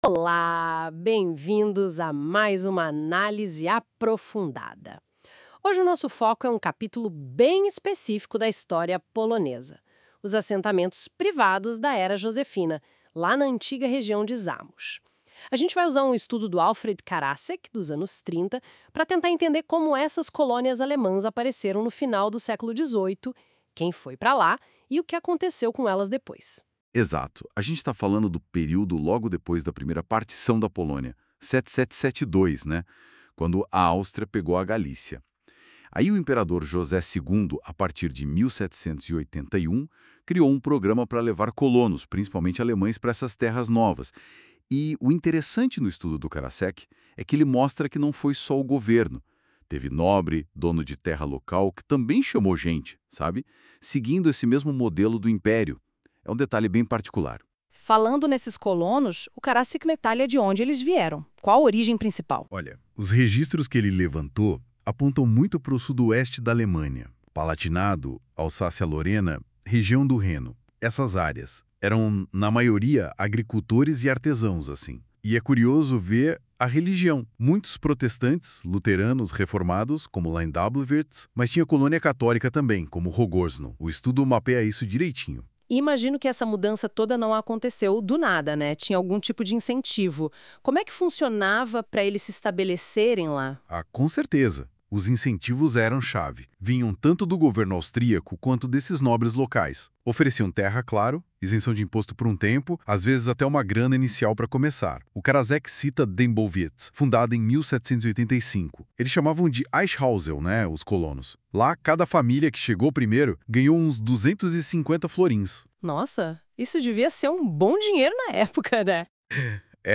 Resumo criado com NotebookLM Pro (Google) on 04.06.2025
• Resumo em áudio (podcast) em português.